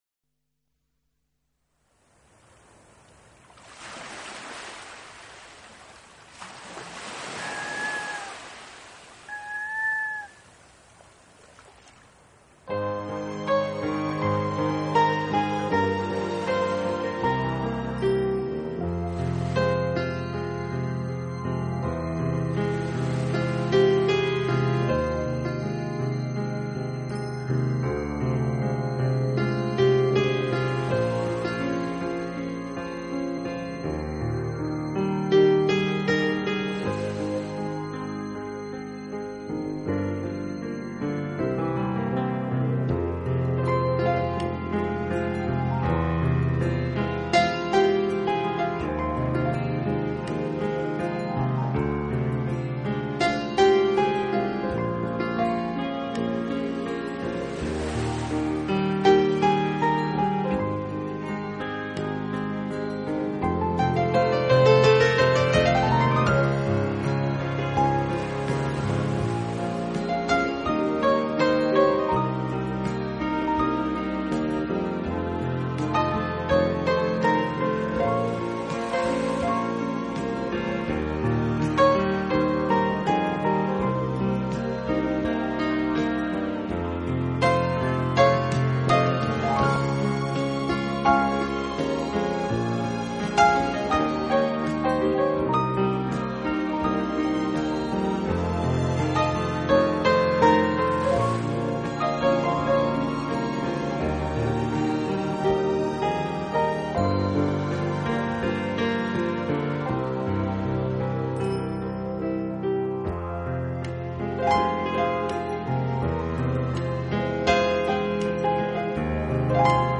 【纯音乐】
没有震耳的低频，但它却是乾乾净净的音像，清清楚楚的定位， 能被像英国BBC这样的传媒